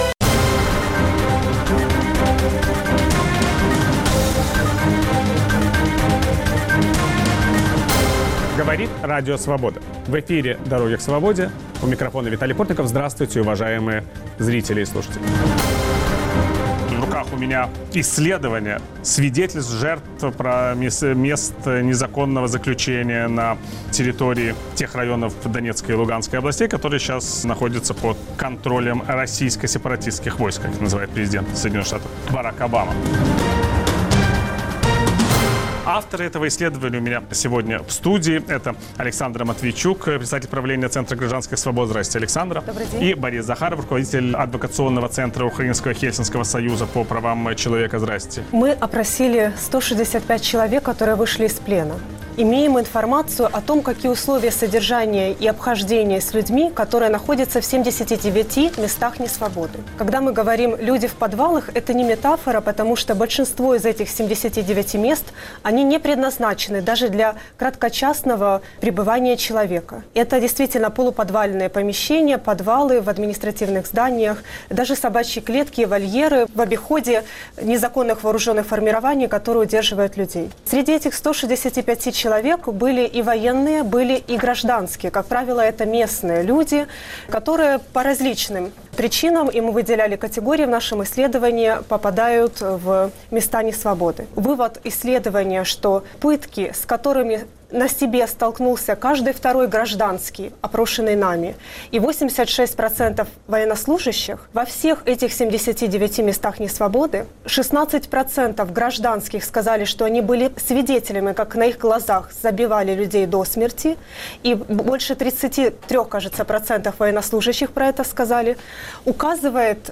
В киевской студии Радио Свобода обсуждаем Украину после Майдана.
Все эти и многие другие вопросы обсуждаем с политиками, журналистами и экспертами.